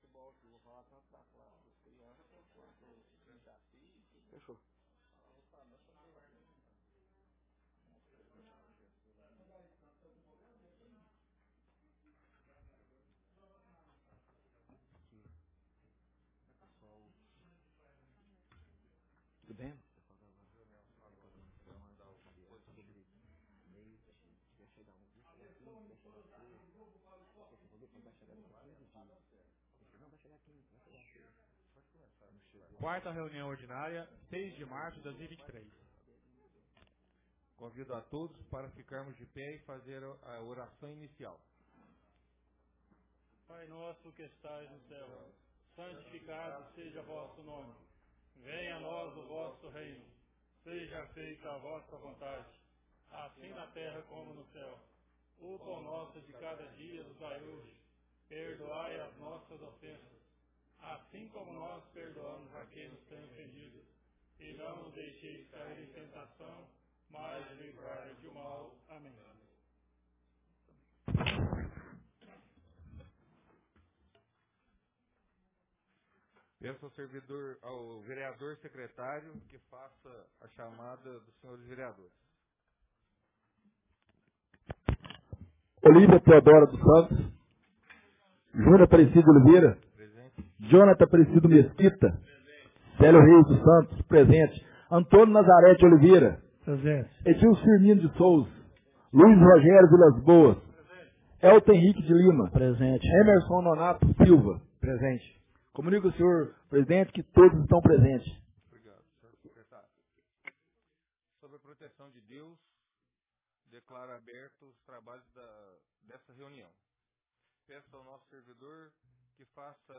Ata da 4ª Reunião Ordinária de 2023